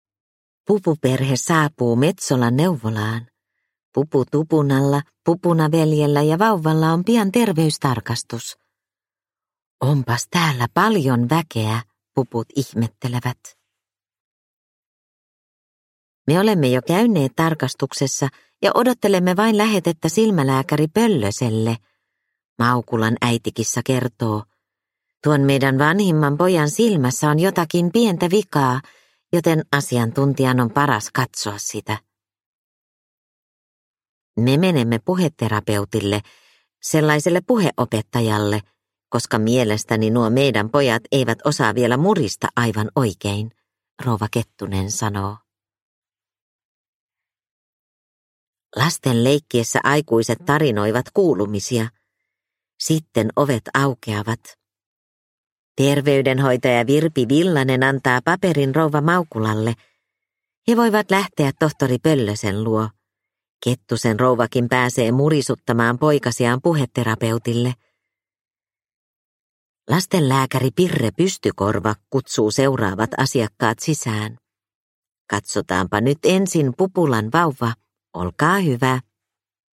Pupu Tupuna neuvolassa – Ljudbok – Laddas ner